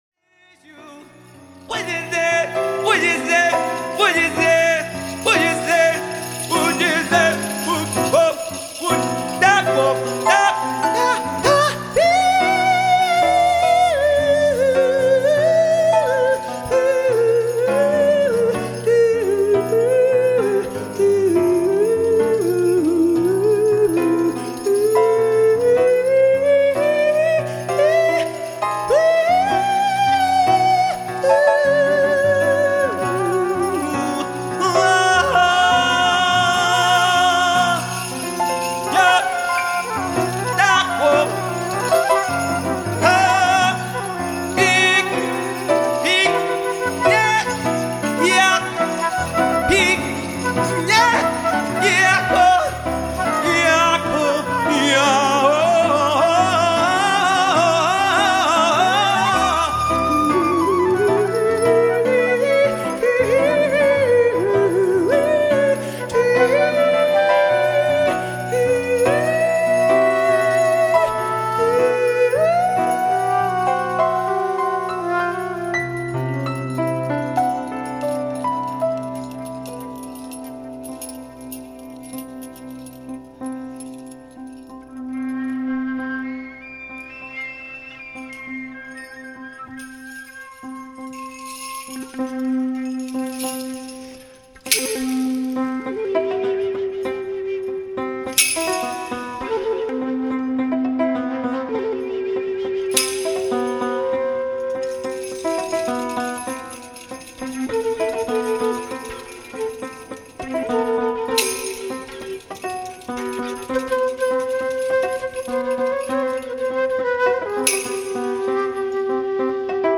vocal / bells
flute
piano